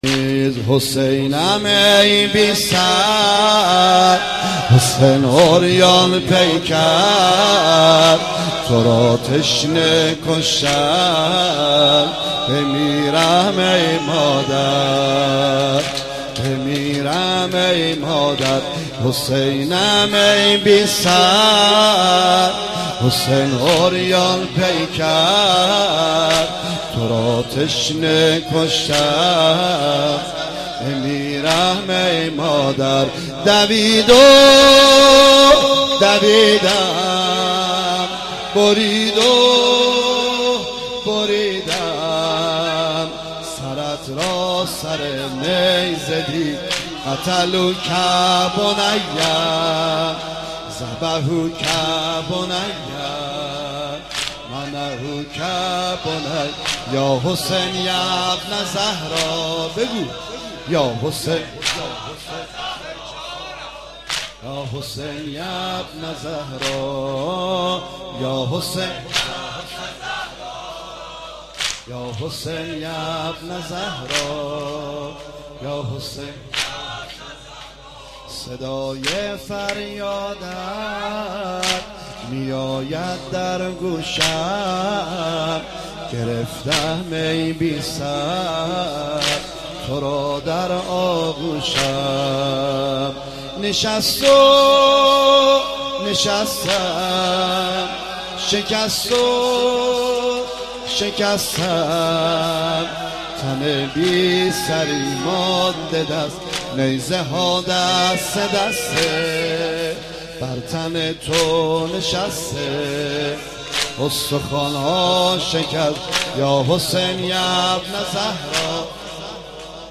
دهه دوم محرم95
زمزمه
زمینه/مسکین هفت سالم
شور/ای ساقی